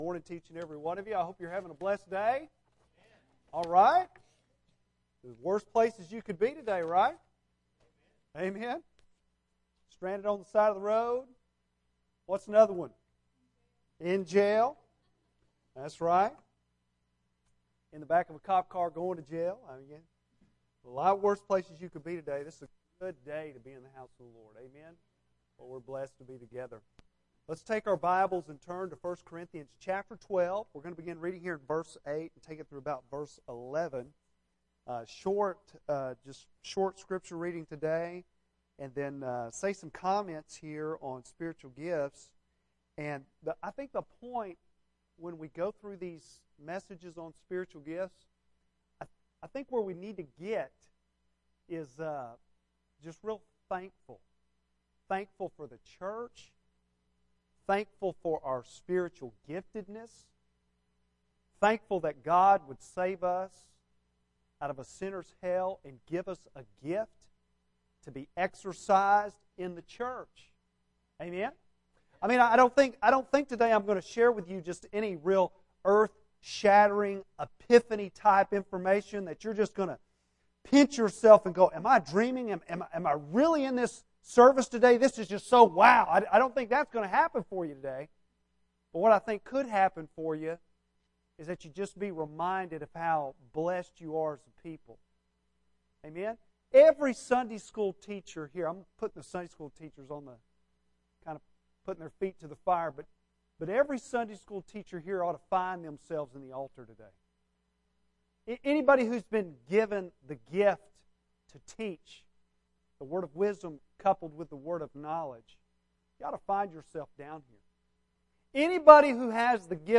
May 17 AM - New Hope Baptist Church